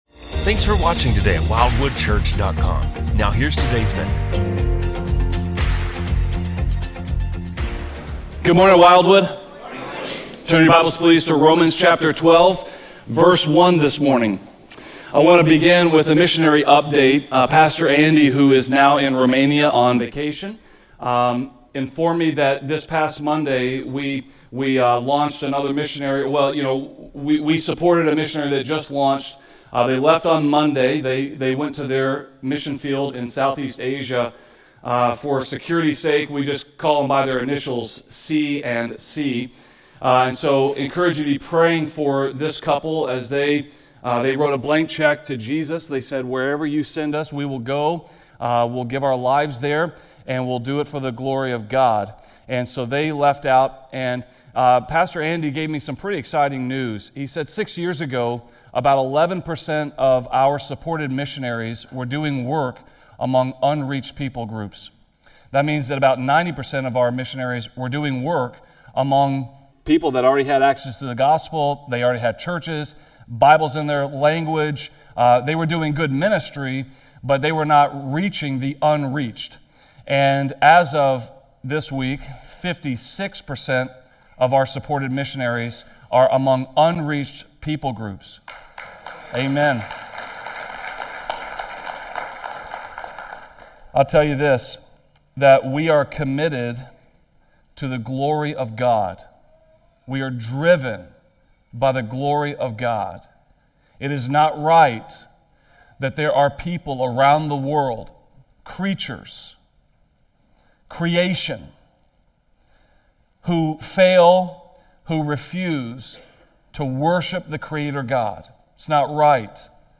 A message from the series "Behold the Glory."